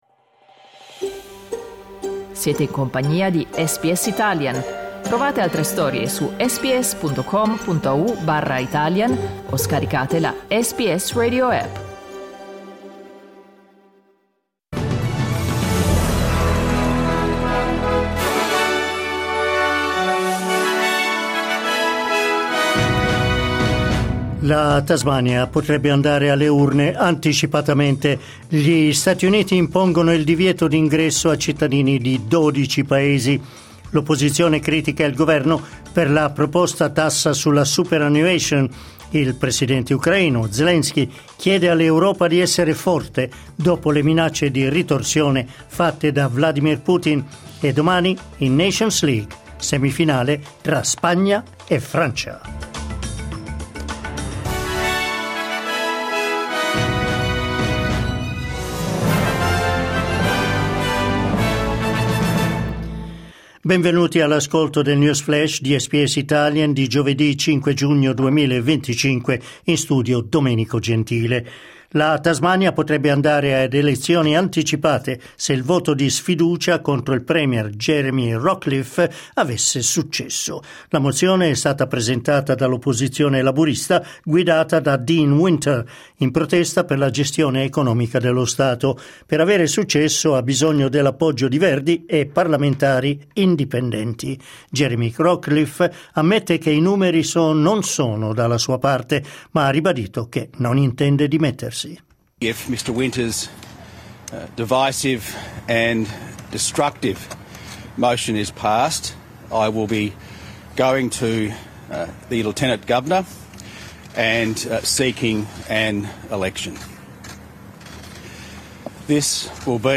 L’aggiornamento delle notizie di SBS Italian.